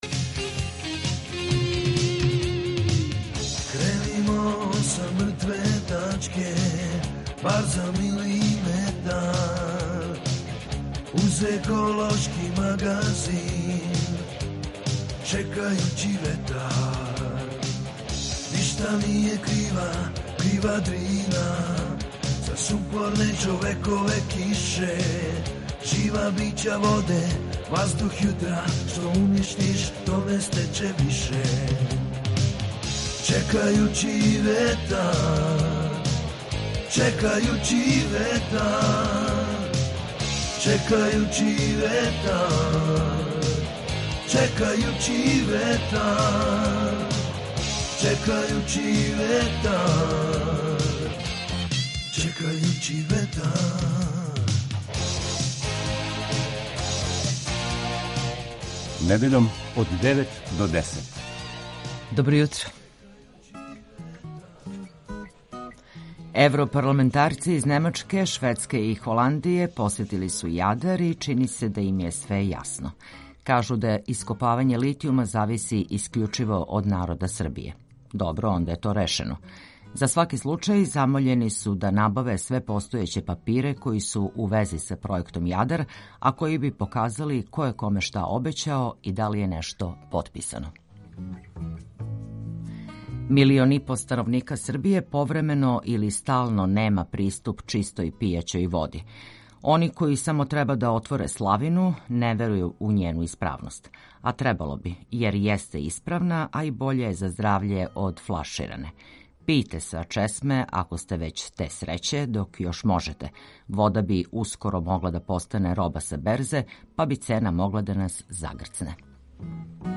ekološki magazin Radio Beograda 2 koji se bavi odnosom čoveka i životne sredine, čoveka i prirode.